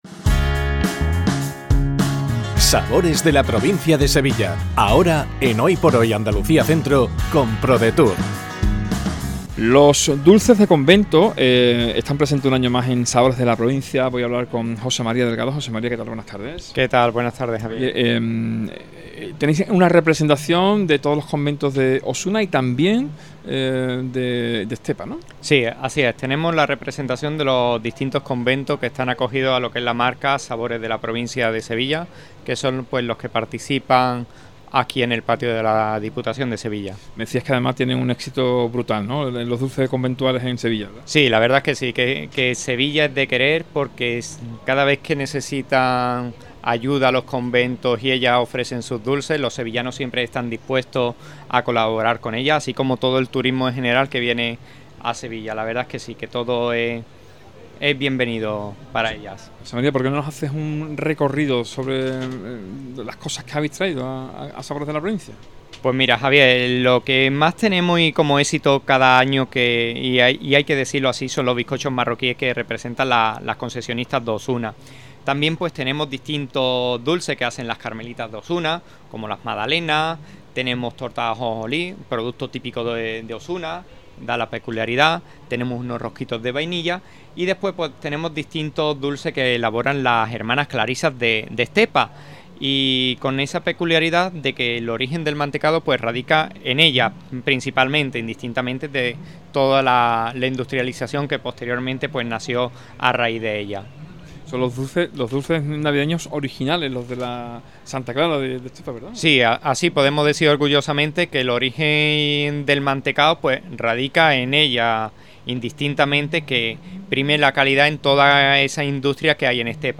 ENTREVISTA DULCES DE CONVENTO SANTA CLARA (ESTEPA)